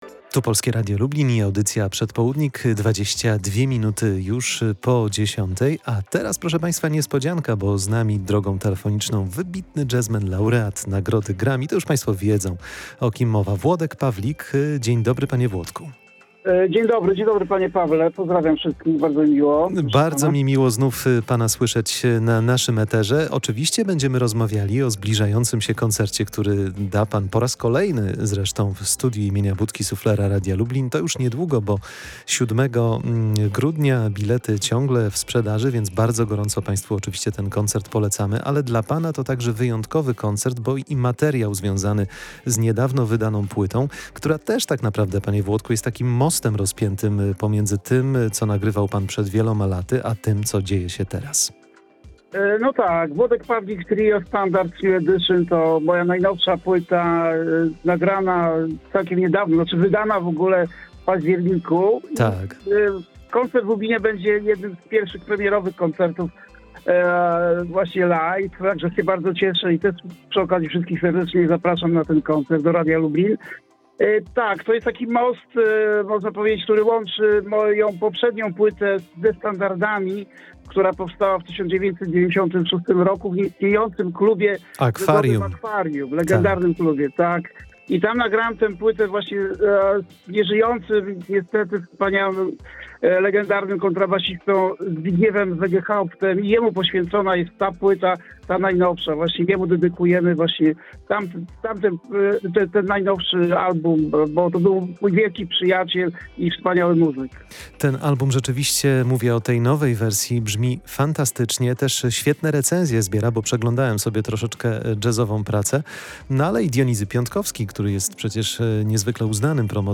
Włodek Pawlik zaprasza na koncert w Radiu Lublin [POSŁUCHAJ ROZMOWY]